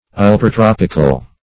Search Result for " ultratropical" : The Collaborative International Dictionary of English v.0.48: Ultratropical \Ul`tra*trop"ic*al\, a. [Pref. ultra- + tropical.]